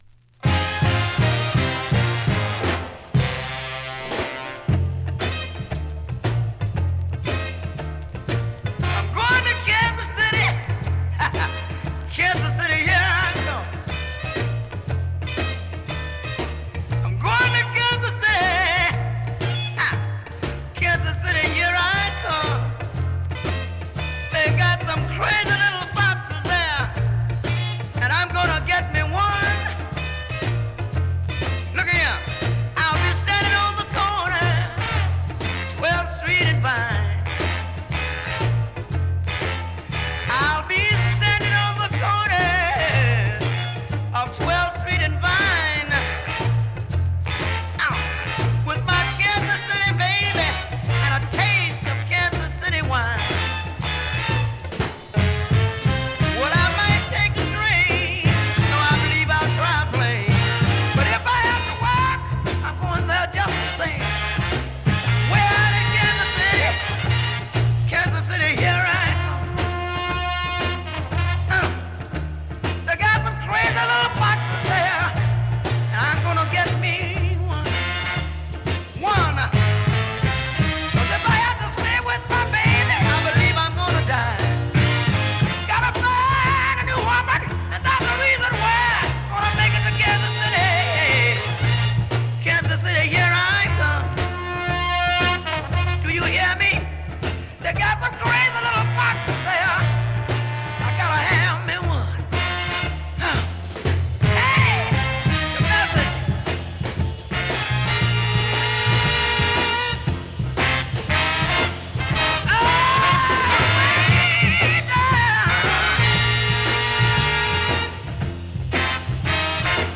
SOUL、FUNK、JAZZのオリジナルアナログ盤専門店